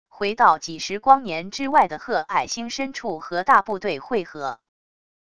回到几十光年之外的褐矮星深处和大部队会合wav音频生成系统WAV Audio Player